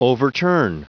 Prononciation du mot overturn en anglais (fichier audio)
Prononciation du mot : overturn
overturn.wav